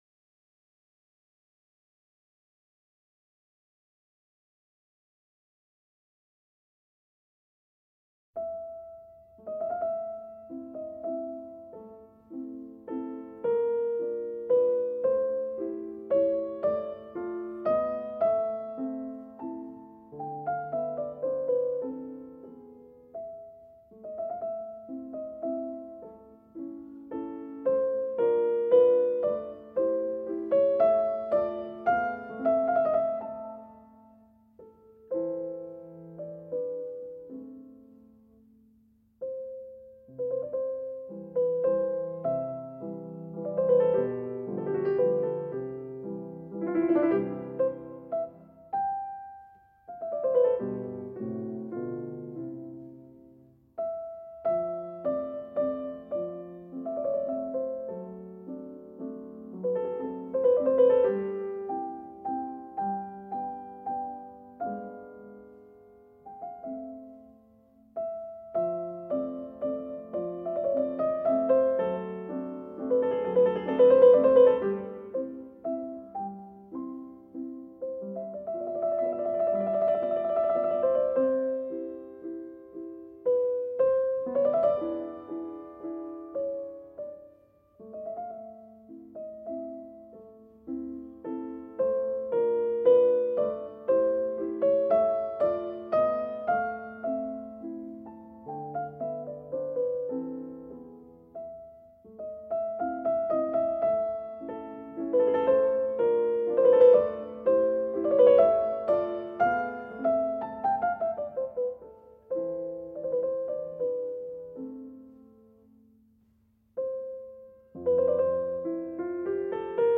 Rondo in A minor